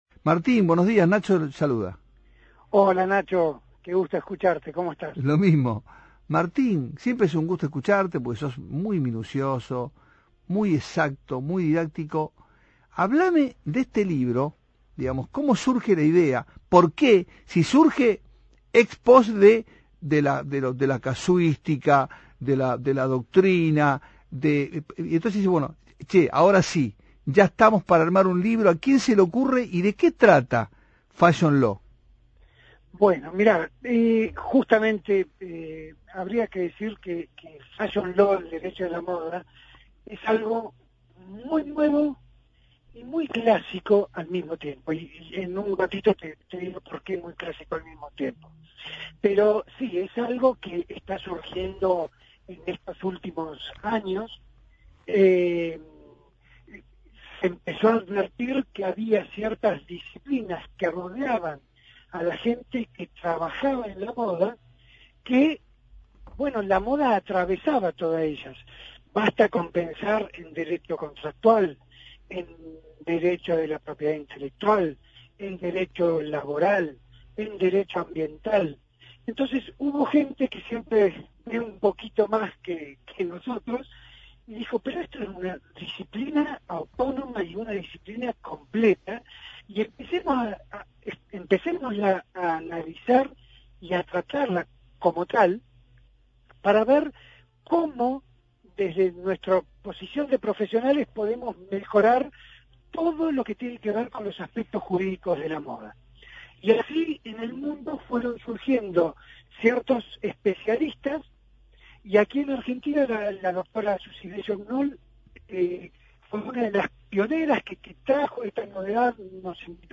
Prensa 2x4 (FM 92.7). Entrevista